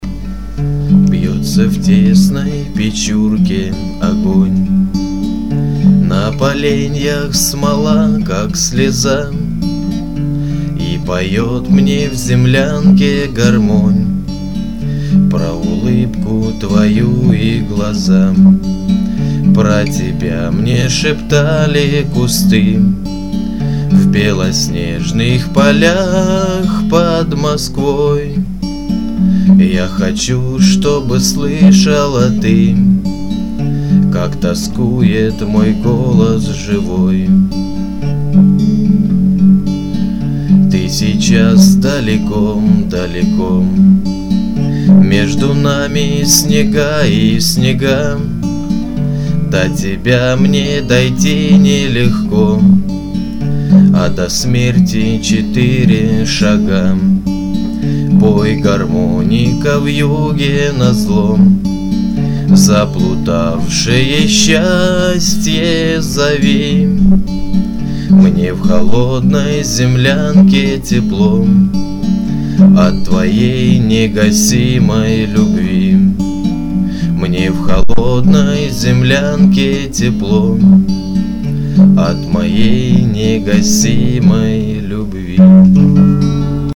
/песни великой отечественной войны под гитару/